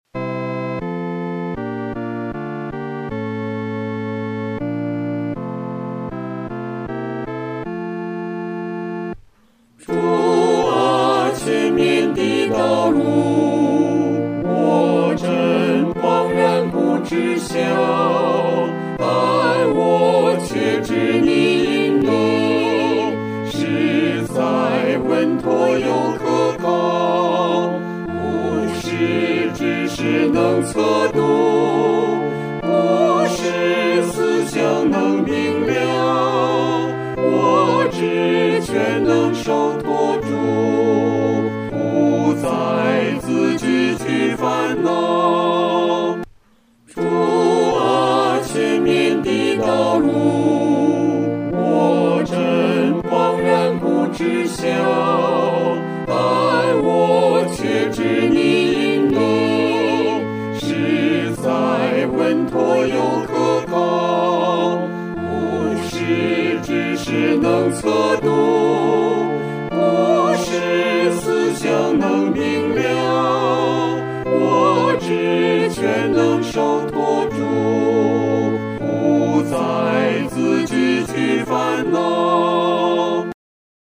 合唱
四声
这首圣诗宜用中速弹唱。